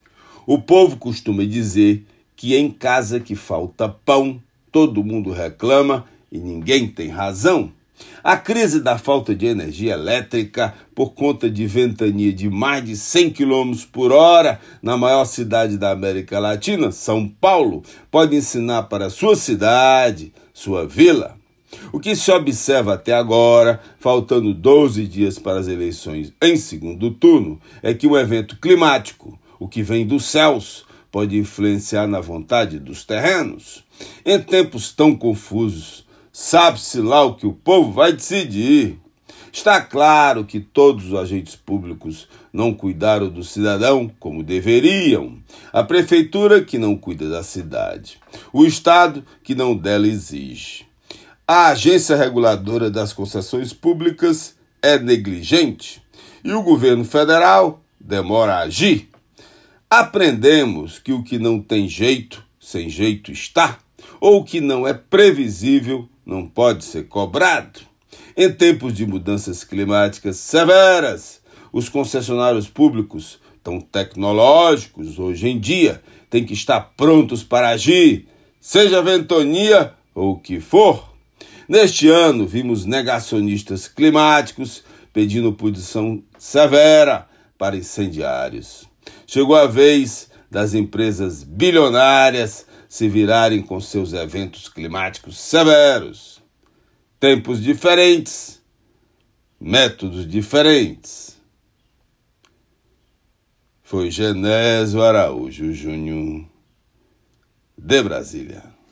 Comentário desta terça-feira
direto de Brasília.